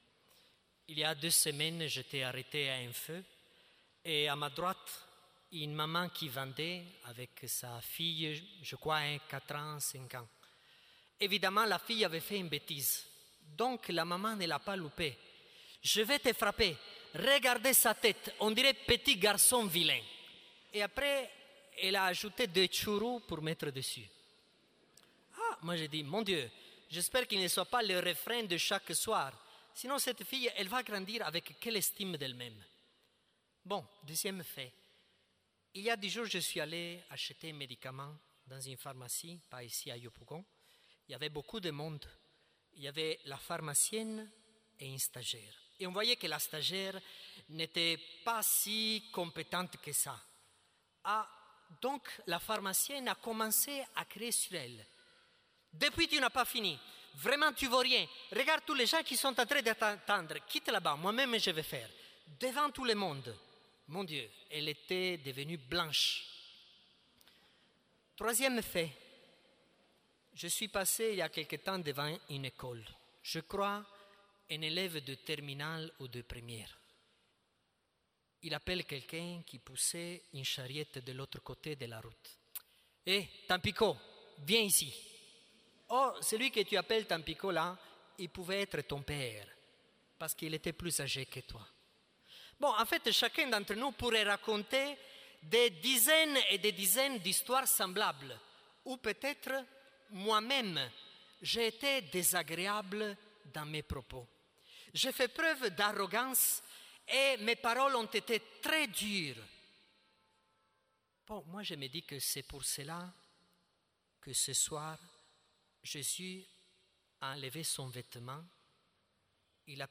Homélie du Jeudi saint 2019